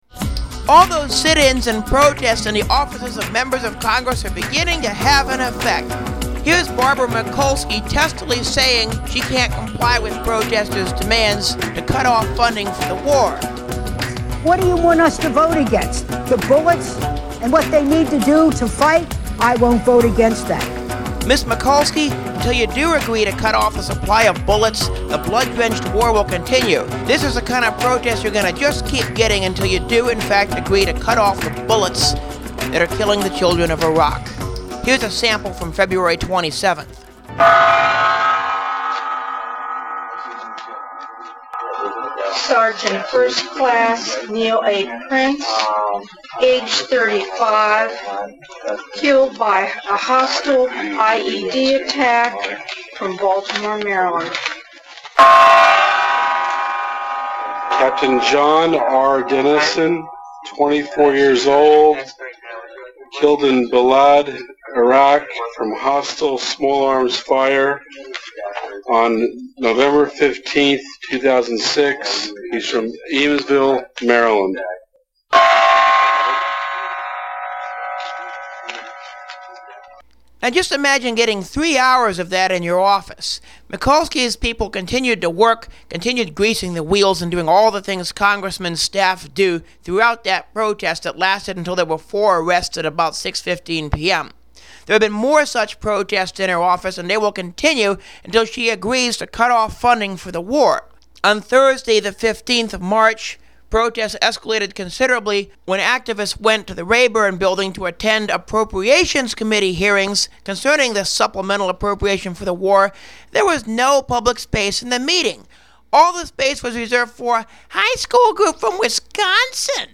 Mikulski/Rayburn demo 4min 44 sec
mikulski_answers_protestors_m15.mp3